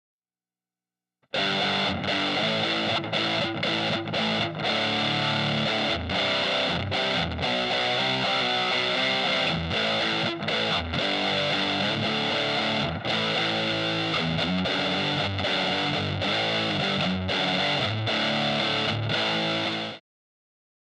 Kinda heavy metal.